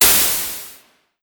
EnemyDead.wav